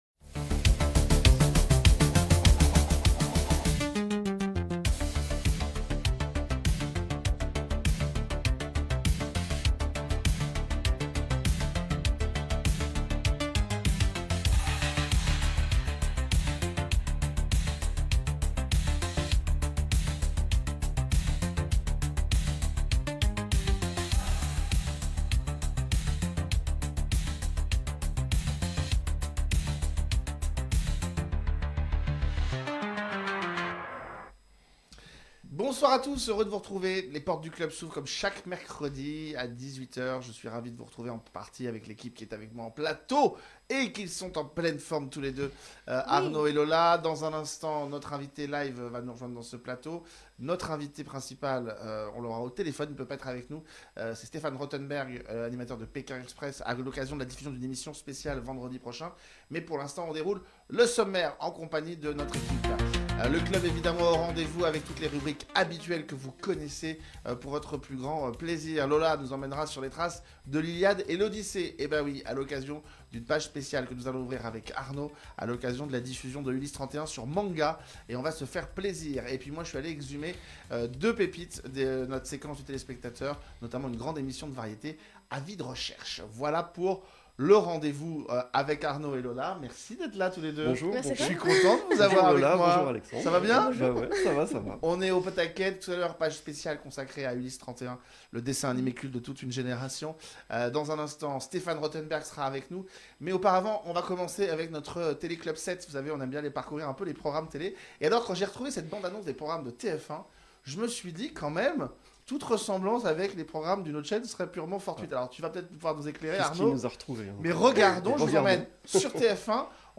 L’invité : Stéphane Rotenberg